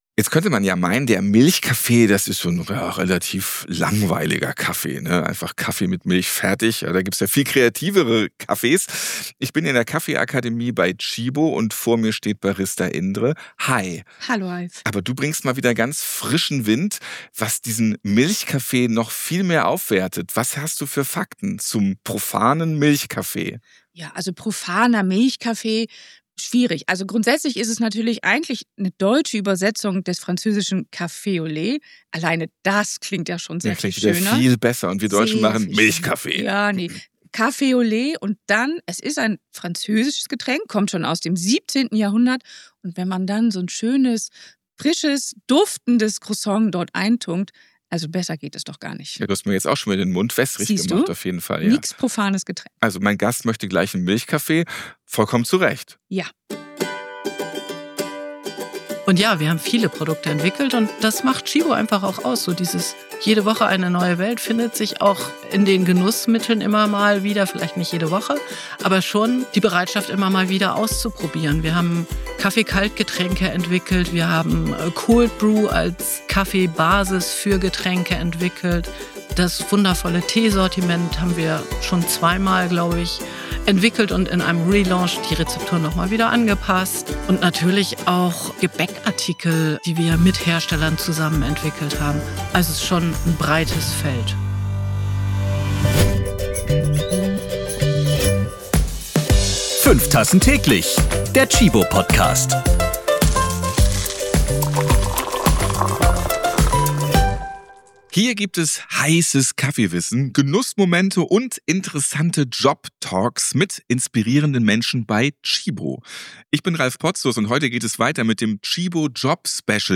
LIVE aufgenommen beim KLARTEXT-Dialog in Berlin: Gibt es morgen noch Kaffee?